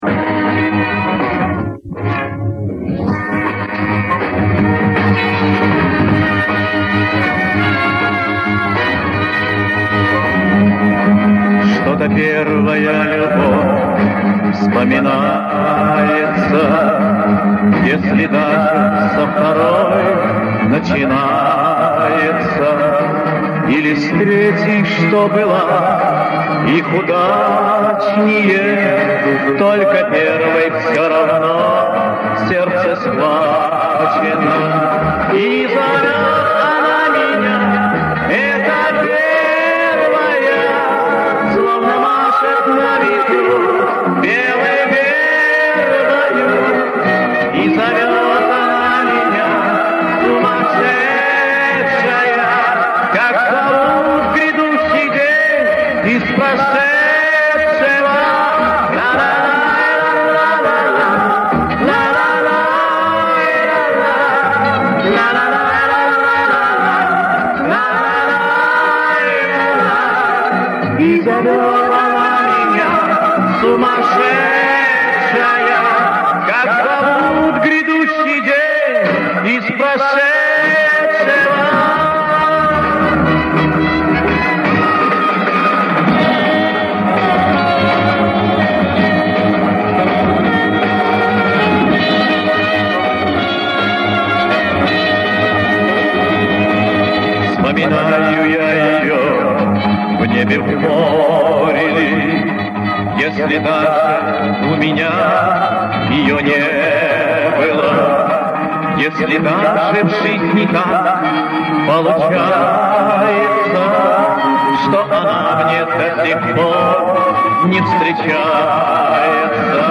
Извиняюсь за качество, но запись явно сделана на концерте.